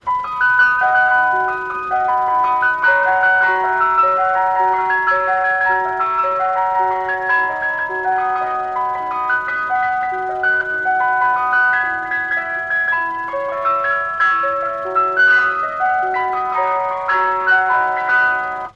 Мелодия фургона с мороженым